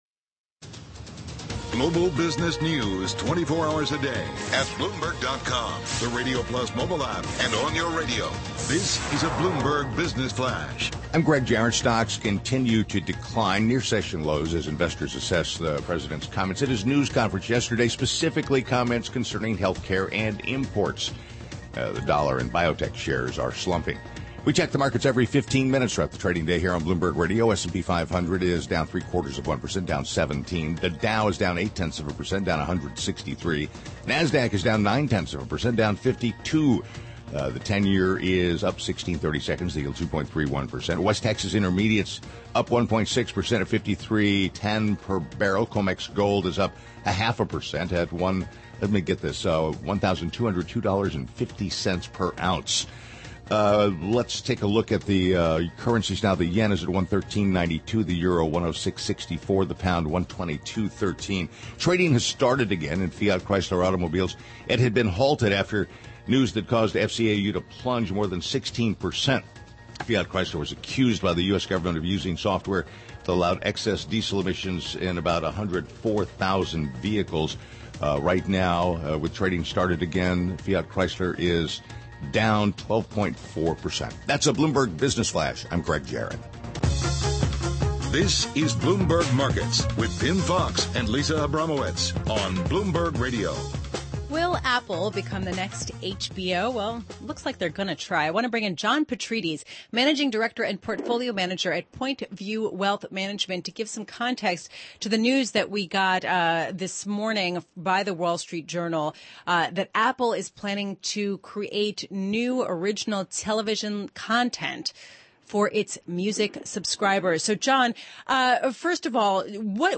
Interview starts at 1:35